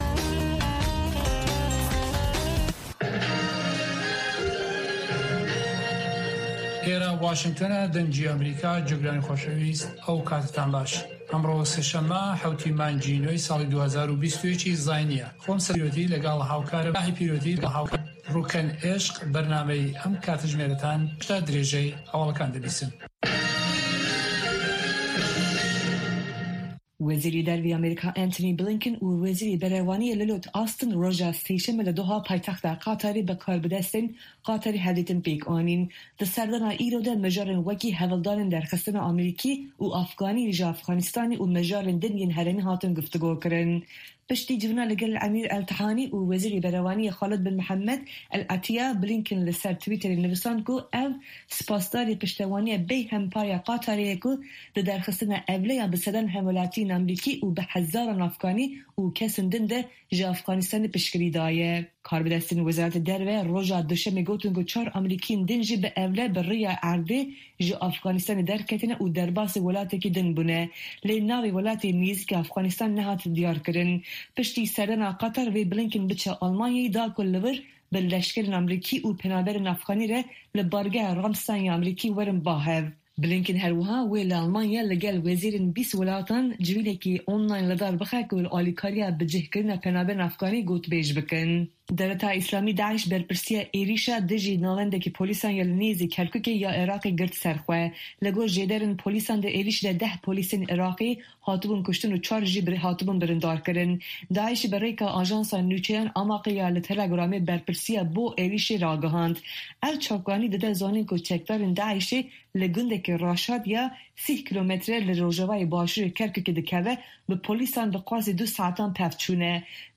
هەواڵەکانی 1 ی پاش نیوەڕۆ